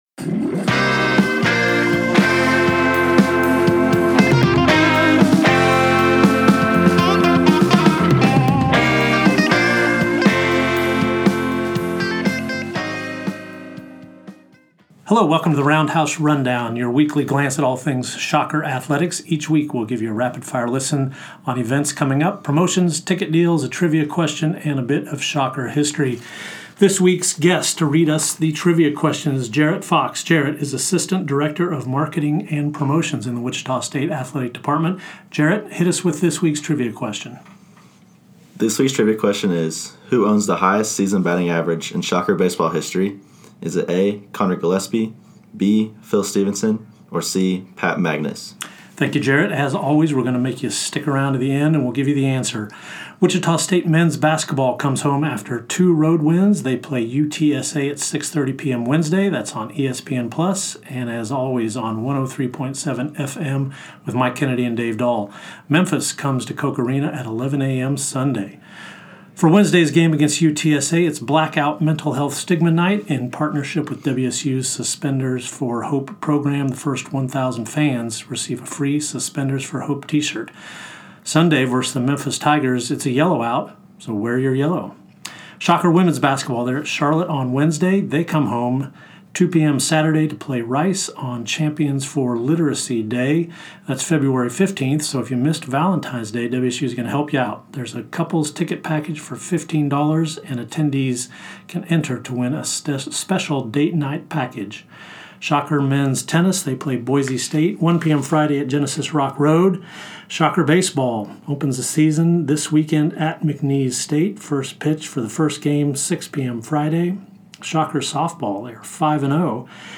Get set for the week with the Roundhouse Rundown podcast, the fastest five minutes in collegiate promotions.